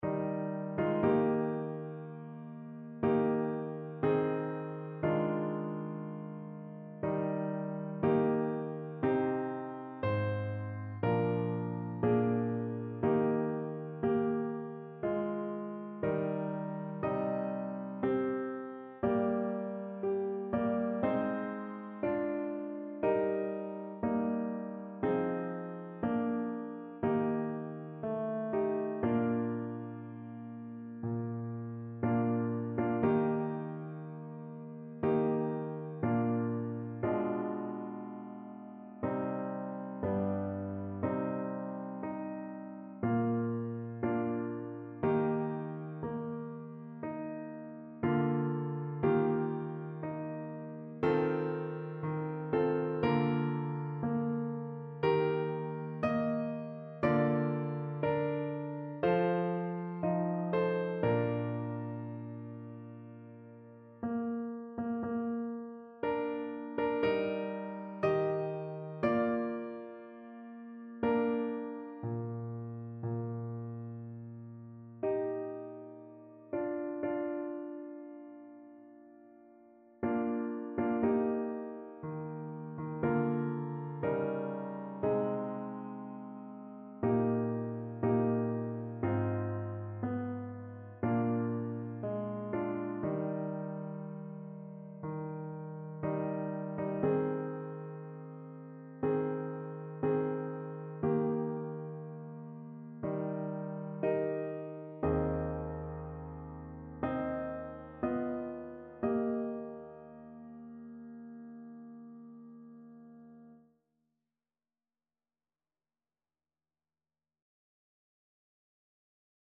4 Stimmen gemischt